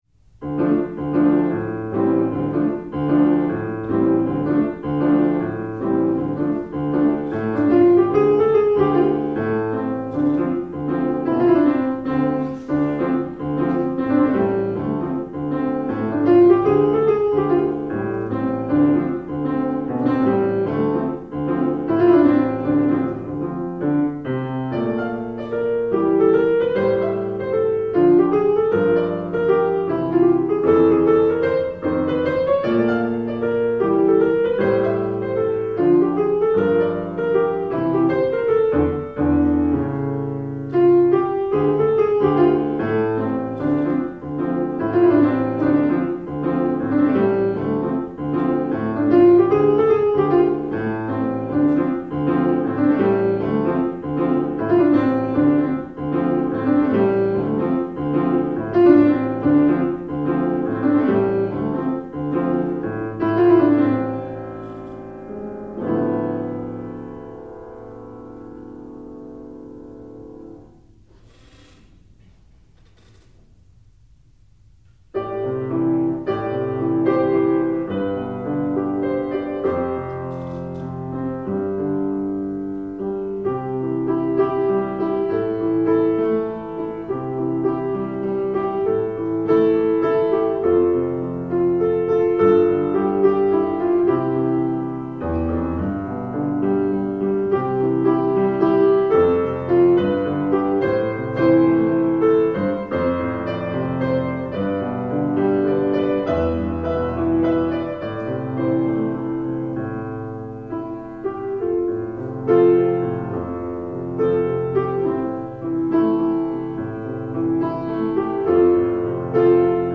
Well a concert happened.